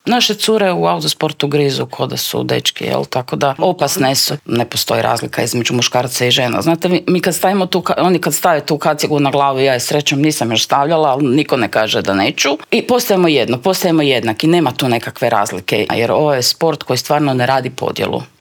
U intervjuu Media servisa o tome su pričale naše gošće